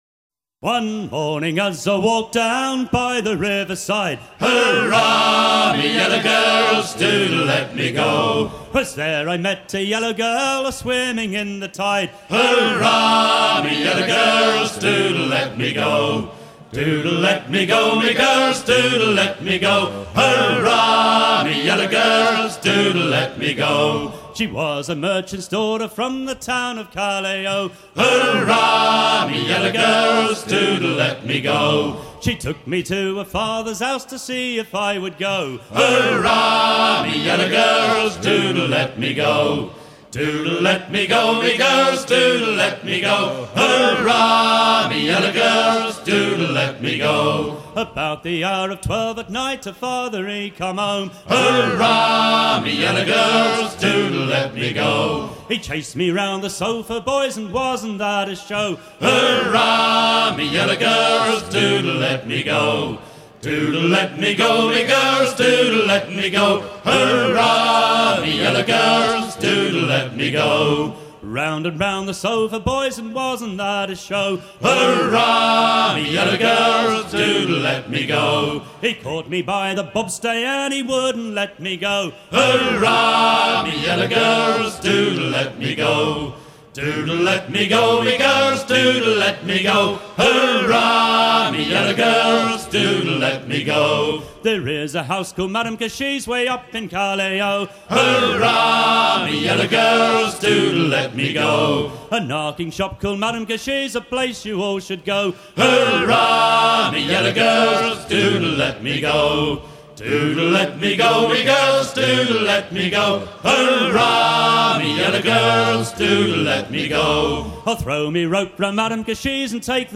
à virer au cabestan
Chants de marins en fête - Paimpol 1997
Pièce musicale éditée